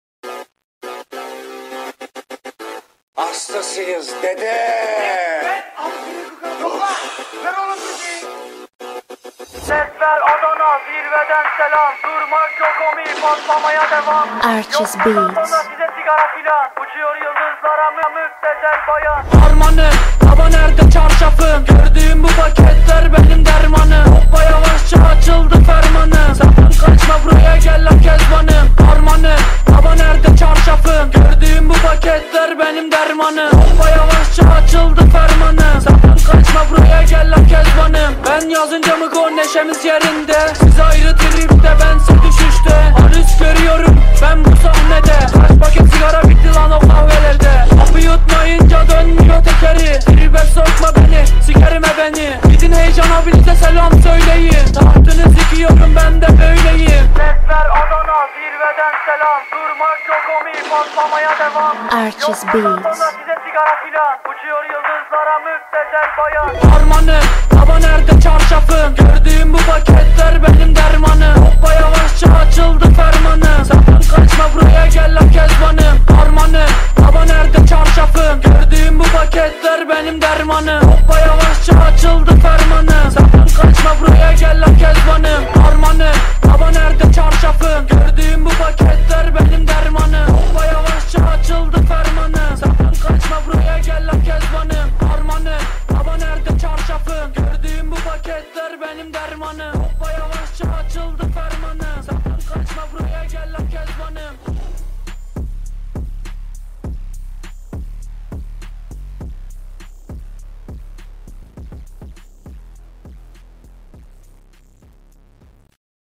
آهنگ رپ ترکی